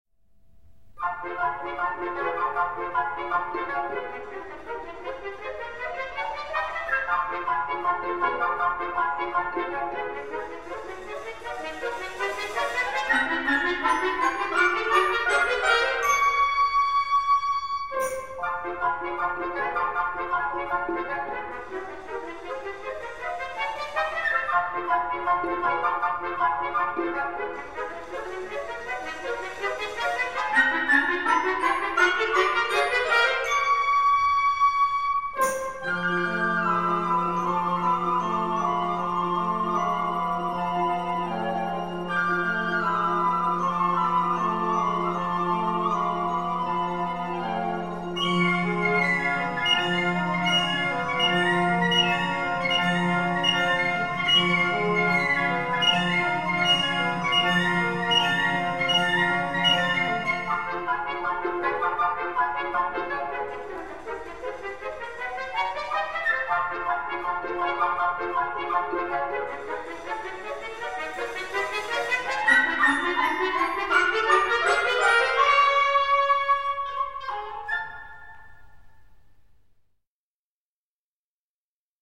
Ein Jahr später kam ein Komponist mit Namen Maurice Ravel zur Welt und fand viele Jahre später, dass die „Bilder einer Ausstellung" in einer Orchesterversion doch wesentlich ausdrucksvoller und einprägsamer seien. 1922 setzte er sich hin und bearbeitete das Werk für Orchester.